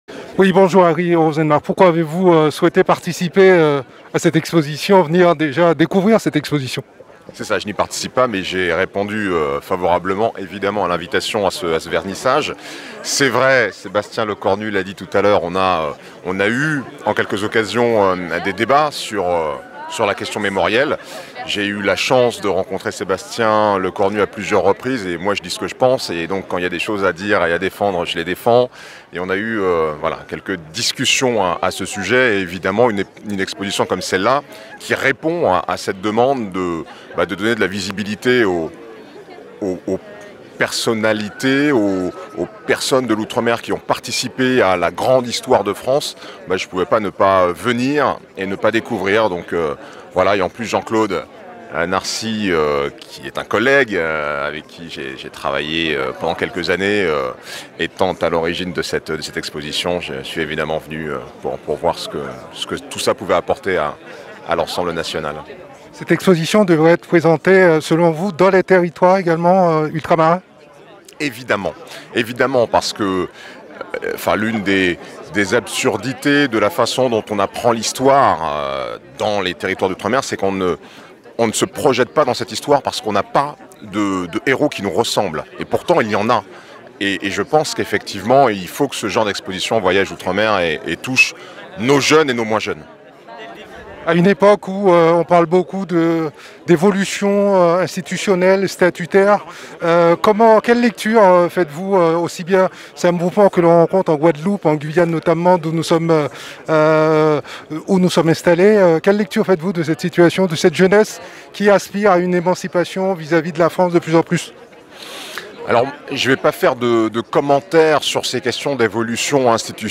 Harry Roselmack au vernissage de l'exposition "Ces héros venus d'Outre-mer"